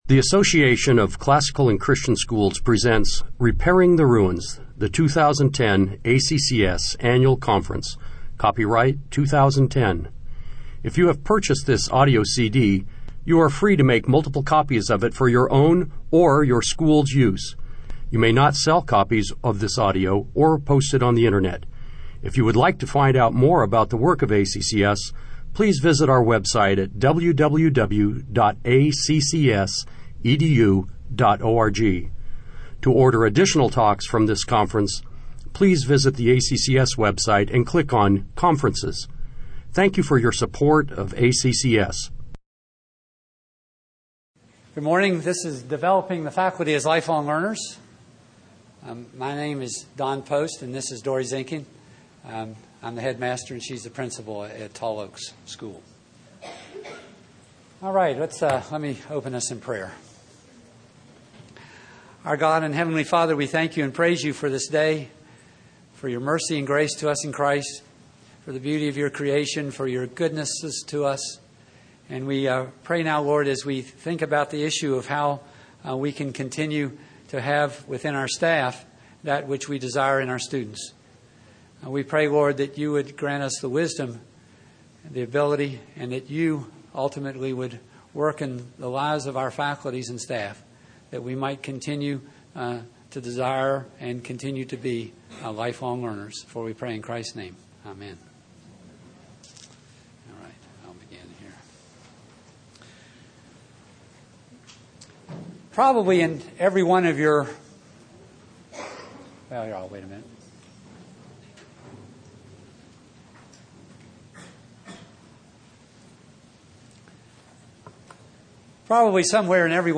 2010 Workshop Talk | 0:52:01 | Leadership & Strategic, Teacher & Classroom, Training & Certification